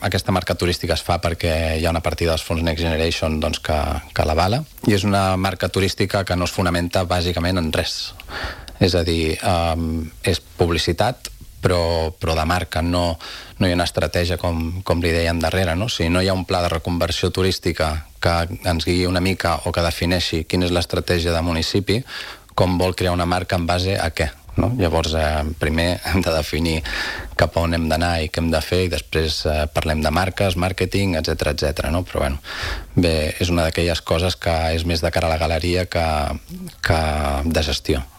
Així de contundent s’ha mostrat Enric Gómez, regidor portaveu d’Estimem Calella, en una entrevista aquest dijous al matinal de RCT, on ha carregat contra la gestió del govern municipal en matèria de promoció de ciutat.